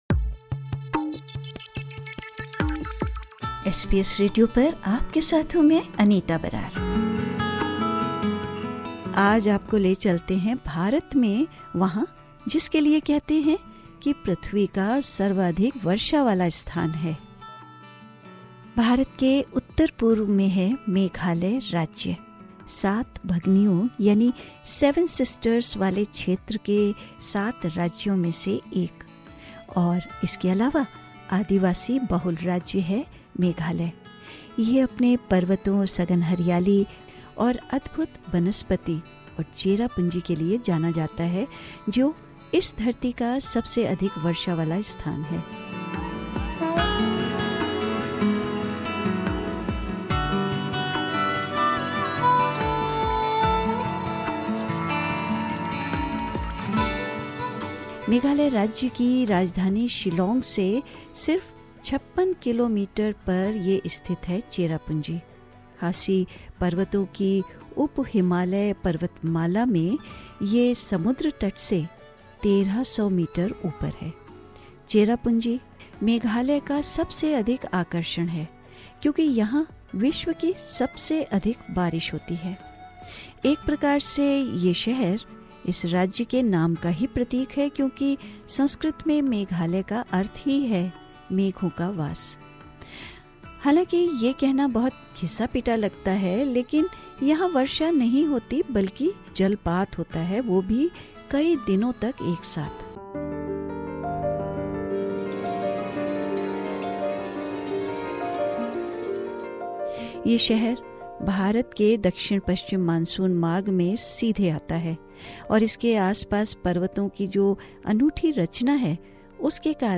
इस संगीतमय पॉडकास्ट के साथ जानिये हमारे साथ इस राज्य के मंत्रमुग्ध कर देने वाले प्राकृतिक आकर्षणों के बारे में ।